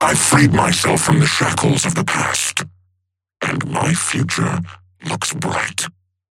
Seven voice line - I freed myself from the shackles of the past, and my future looks bright.